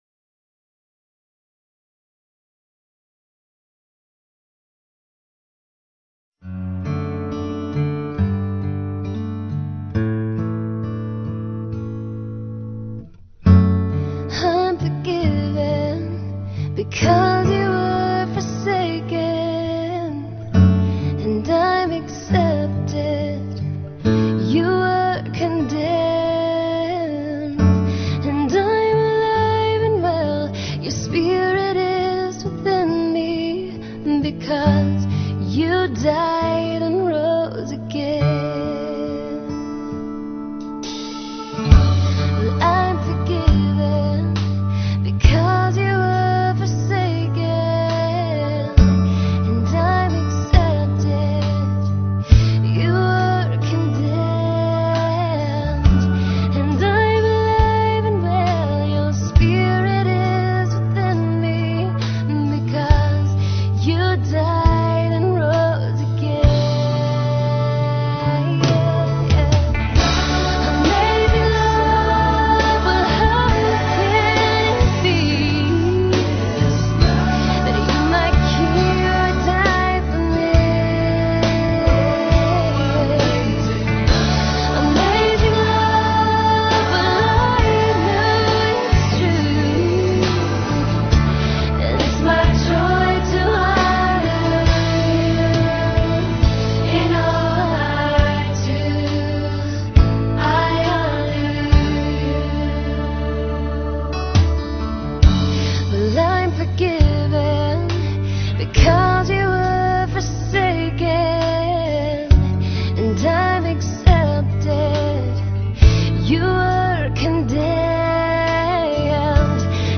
Song of Praise : Amazing Love